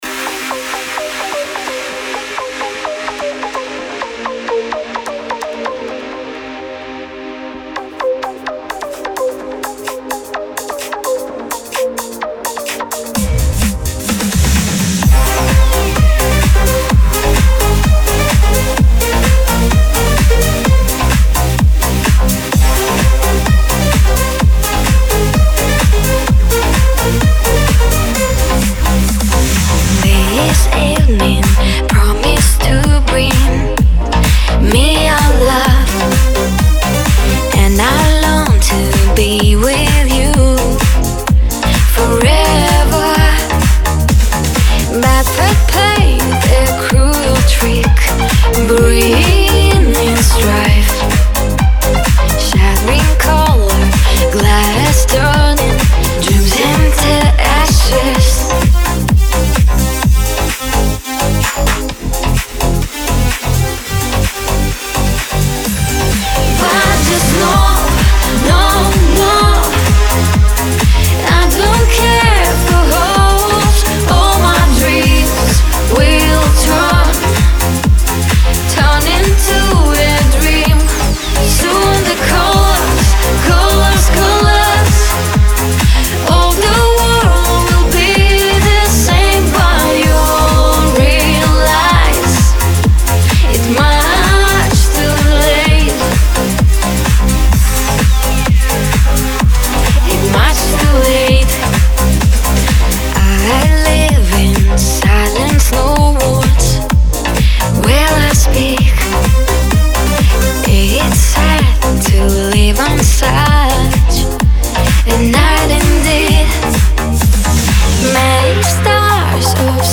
диско
dance